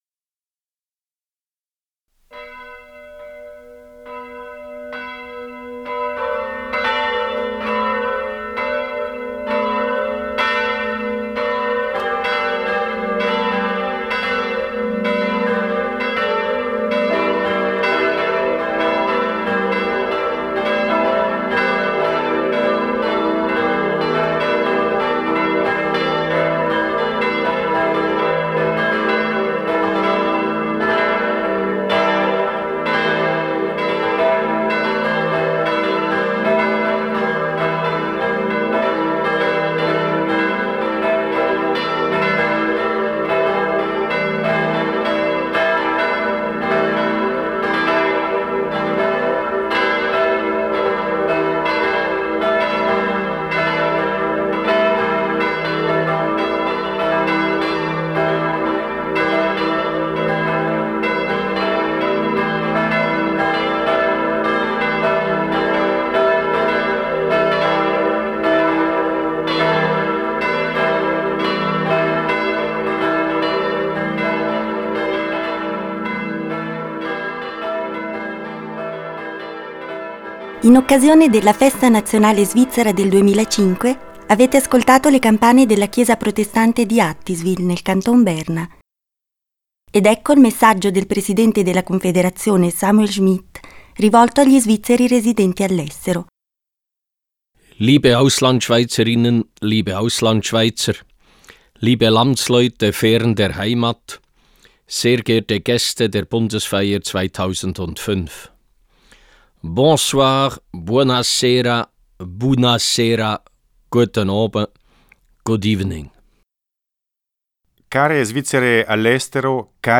Allocuzione del presidente della Confederazione Moritz Leuenberger per gli Svizzeri all'estero.